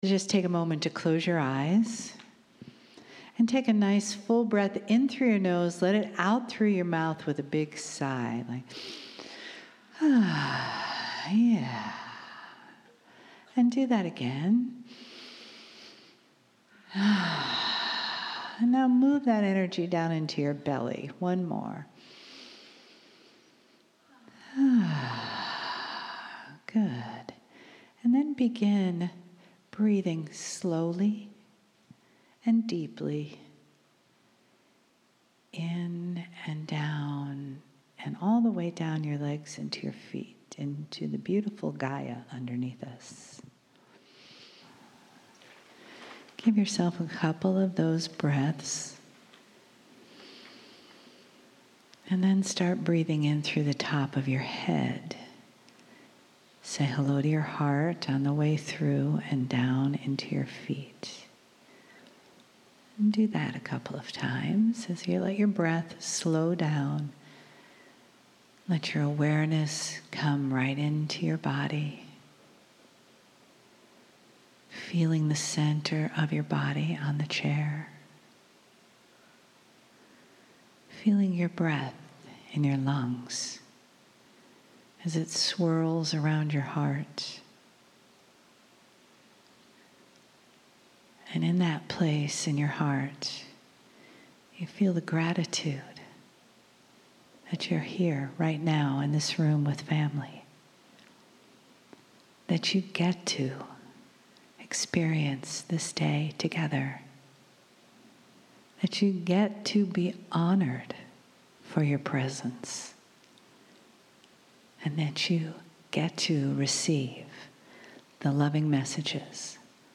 Free download - Stamford, Connecticut, August 12-13, 2017
KRYON CHANNELLING